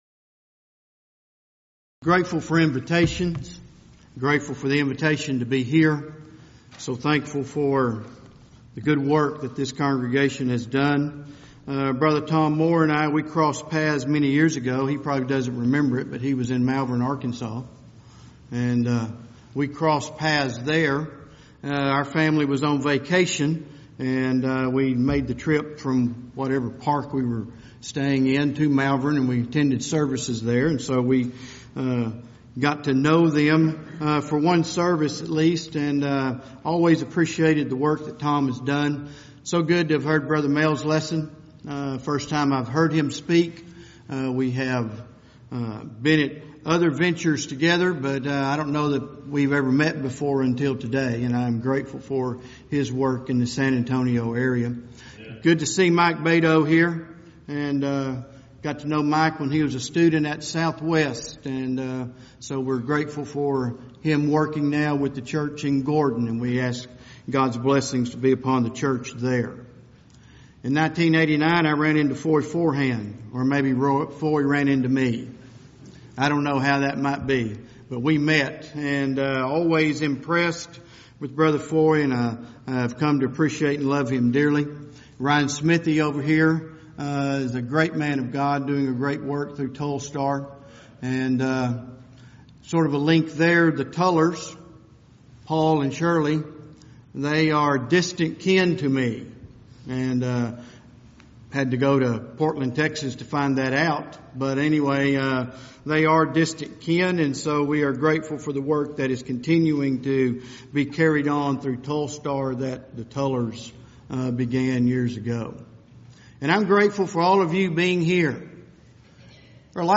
If you would like to order audio or video copies of this lecture, please contact our office and reference asset: 2017BackToTheBible02 Report Problems